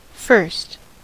Ääntäminen
Ääntäminen US : IPA : /ˈfɝst/ Tuntematon aksentti: IPA : /ˈfɜːst/ Haettu sana löytyi näillä lähdekielillä: englanti Käännöksiä ei löytynyt valitulle kohdekielelle. 1st on sanan first lyhenne.